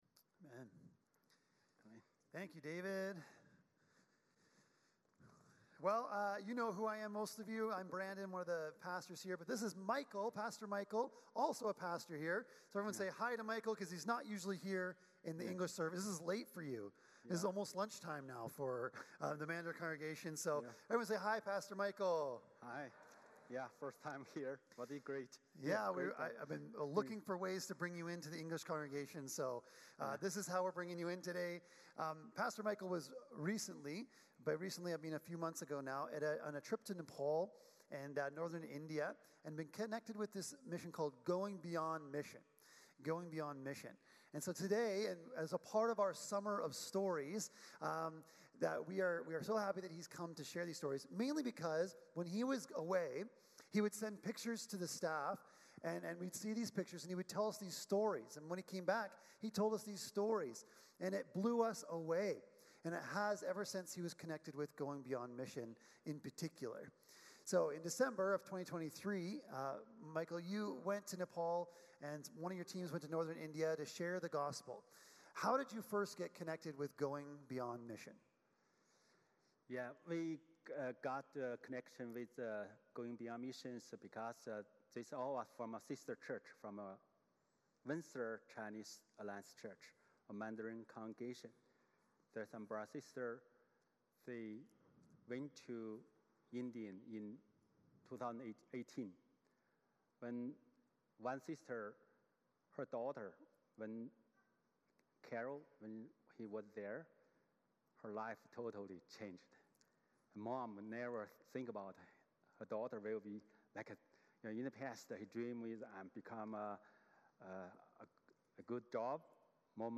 8 Service Type: Sunday Morning Service Passage